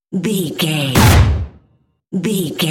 Dramatic stab drum hit
Sound Effects
Atonal
heavy
intense
dark
aggressive